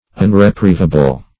Unreprievable \Un`re*priev"a*ble\, a. Not capable of being reprieved.